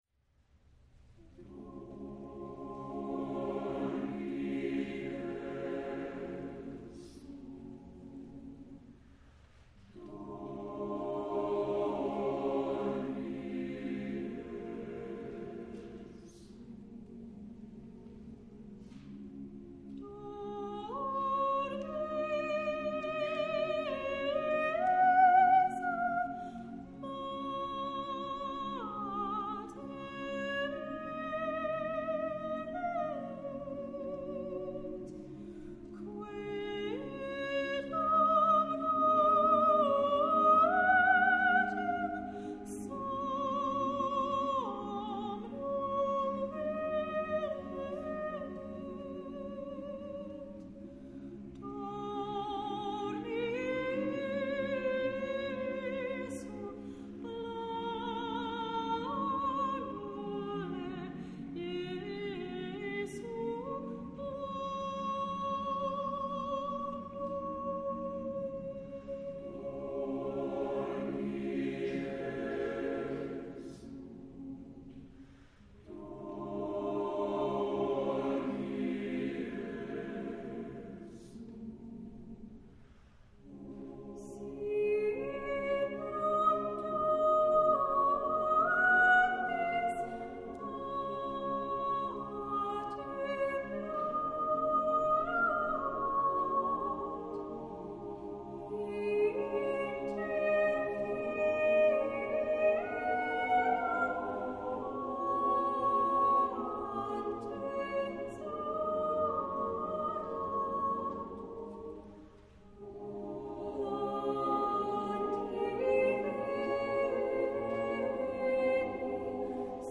Voicing: SSATB a cappella